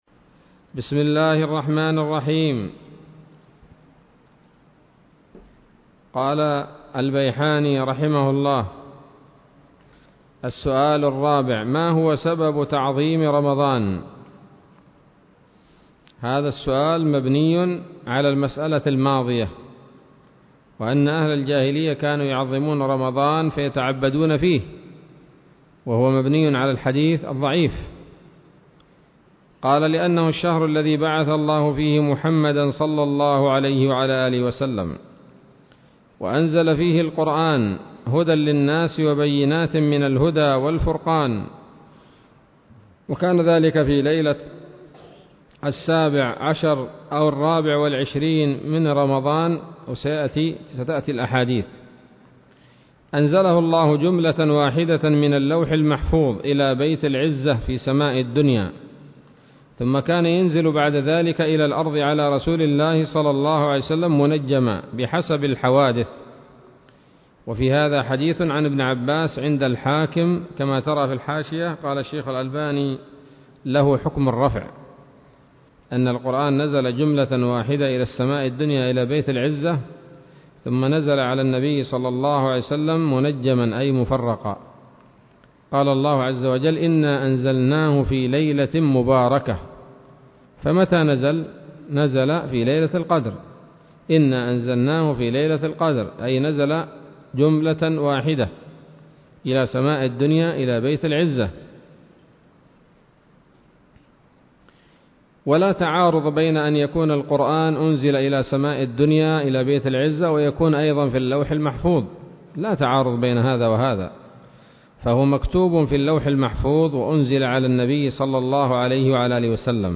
الدرس الخامس من تحفة رمضان للعلامة البيحاني [1443هـ]